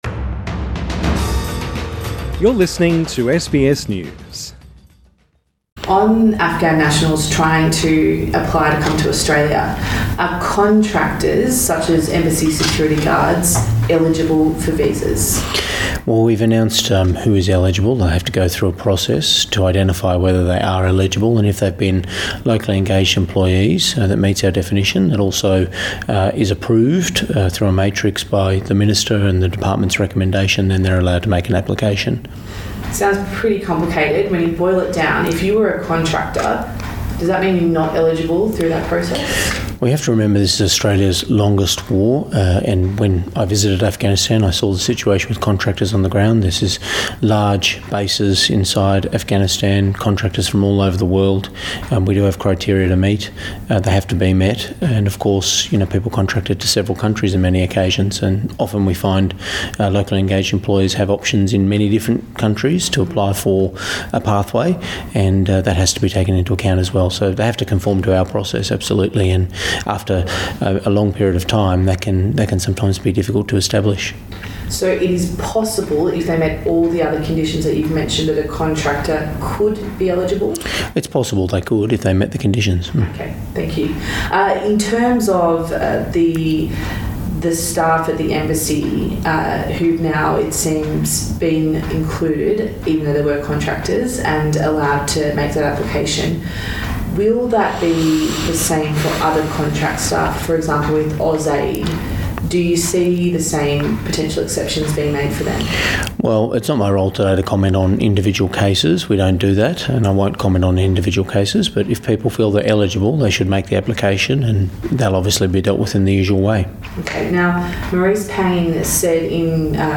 Interview with Immigration Minister Alex Hawke